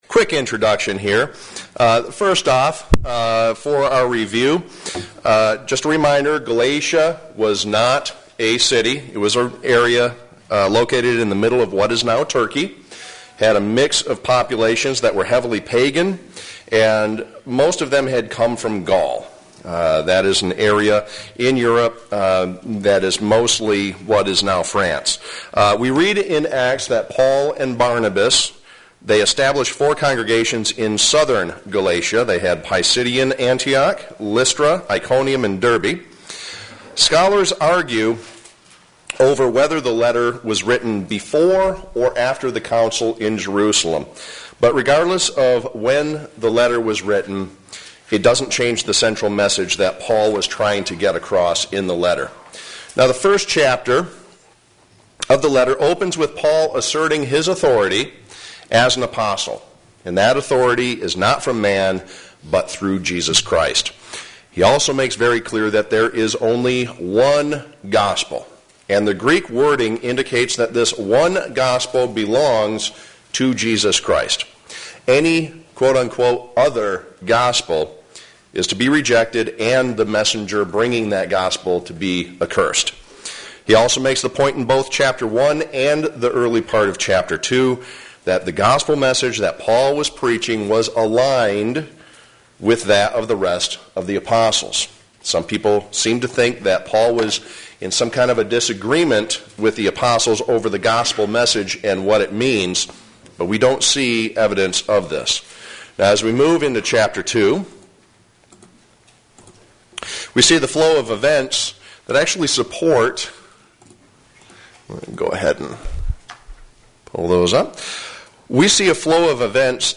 Given in Flint, MI